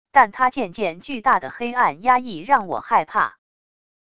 但它渐渐巨大的黑暗压抑让我害怕wav音频生成系统WAV Audio Player